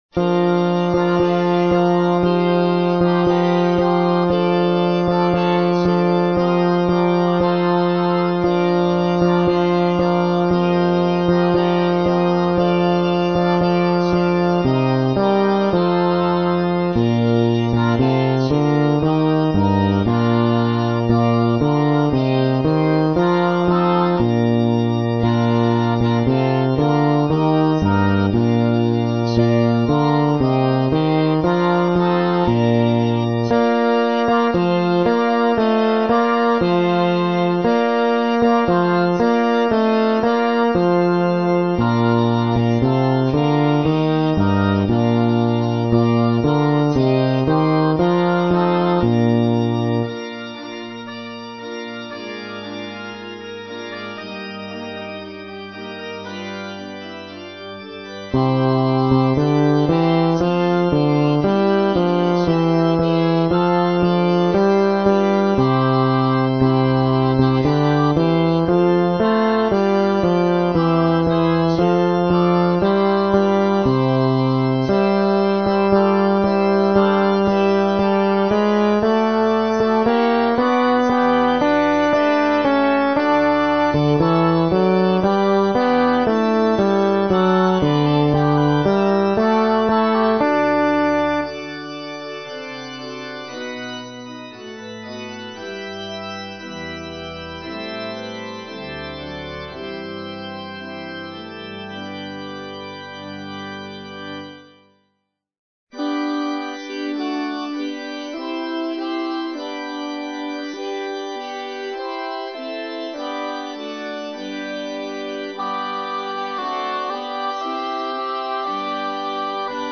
バス（フレットレスバス音）
＊テンポはすべて一定にしてあるので音取りのみに使用し、実際に歌うときは楽譜の指示、指揮者を見る。